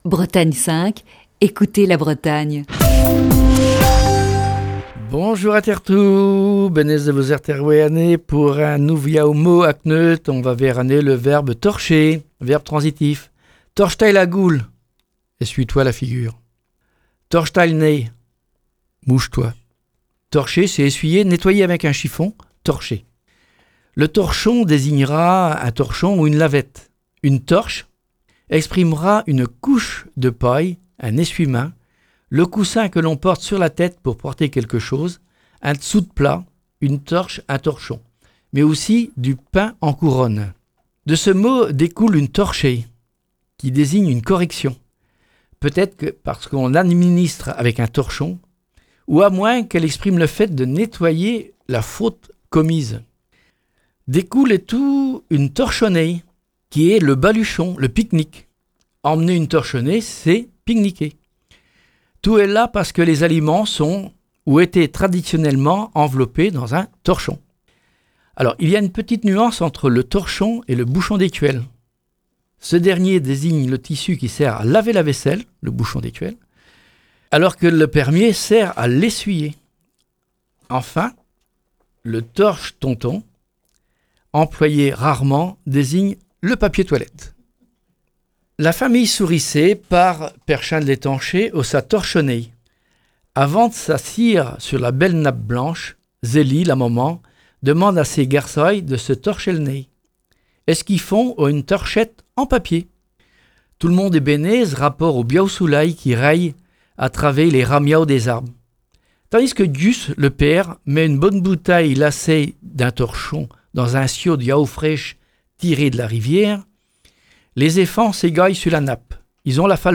Chronique du 30 juillet 2020.